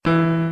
FueraDeEscala/Assets/Game Kit Controller/Sounds/Piano Keys C Scale New/e1.wav at fd87a6ffd54a003899c2dc869cf5a4687d7b7b3b